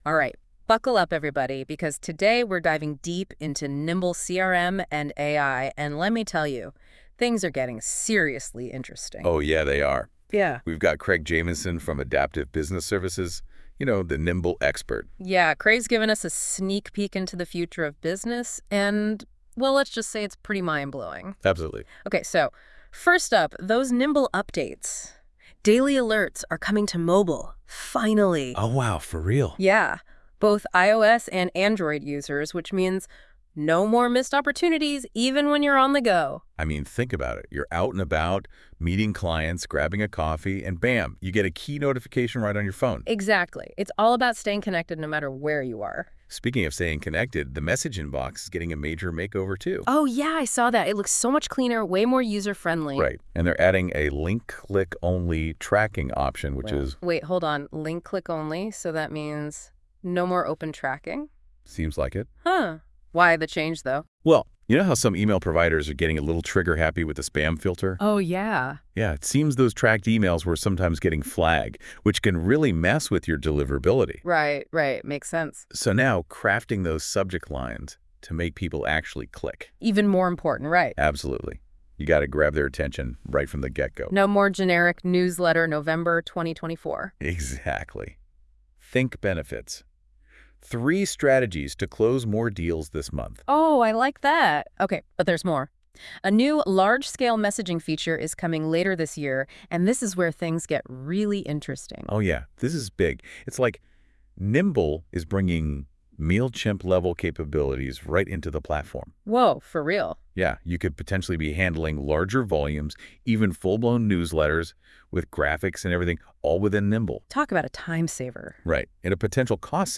Catch the AI generated podcast!